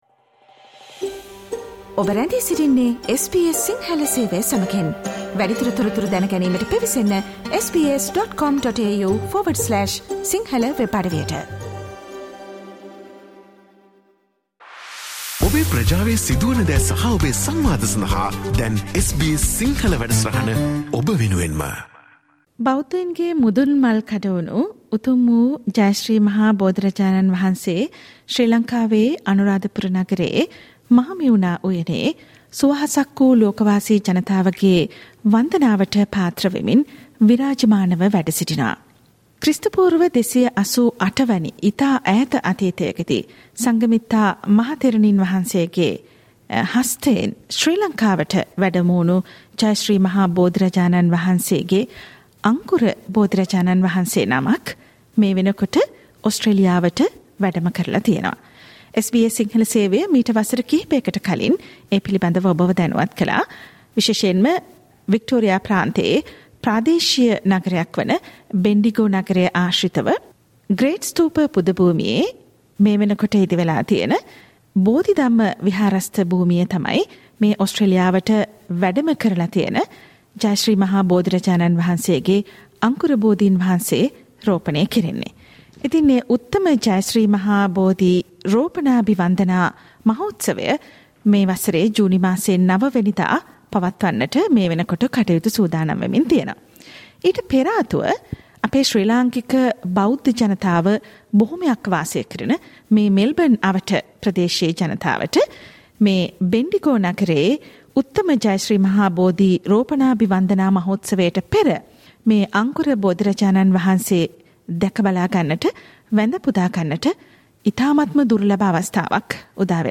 This discussion brings you information on the display ceremony of the sacred sapling of Jaya Sri Maha Bodhi, at Dhamma Sarana Buddhist Vihara premises in Keysborough.